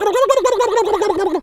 turkey_ostrich_gobble_16.wav